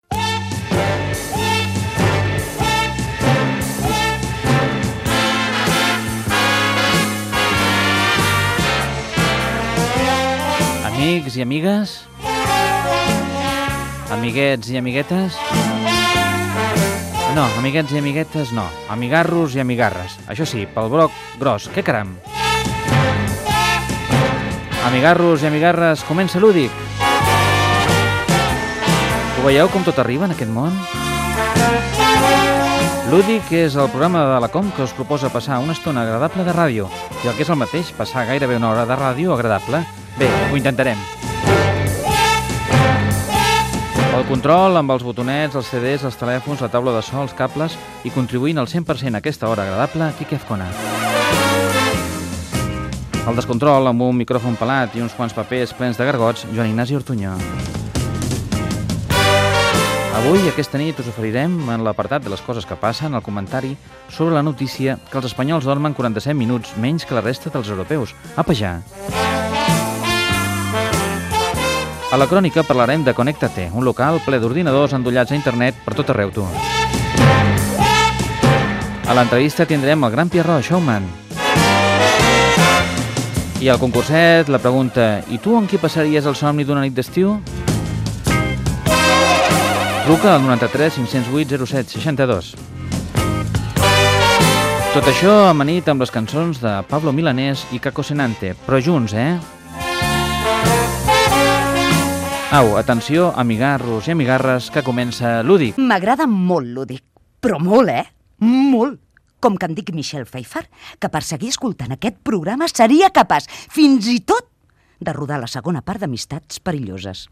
Inici del programa i sumari
Entreteniment